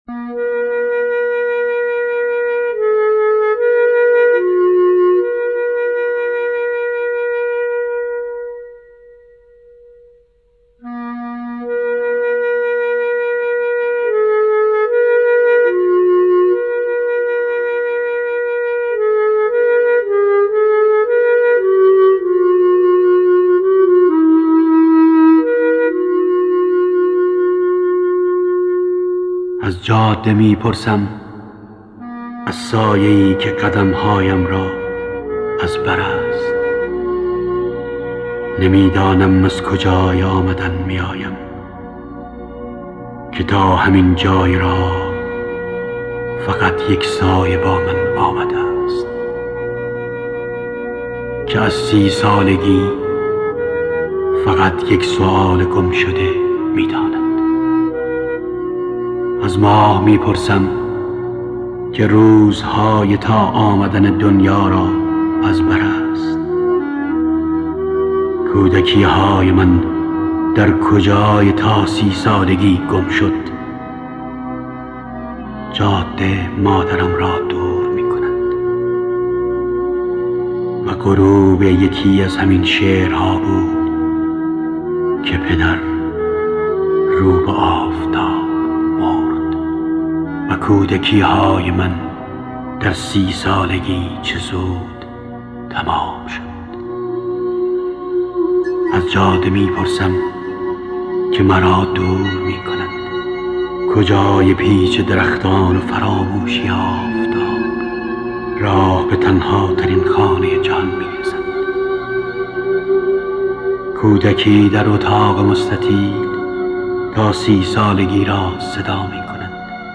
دانلود دکلمه جاده مرا دور میکند  با صدای احمدرضا احمدی
گوینده :   [احمدرضا احمدی]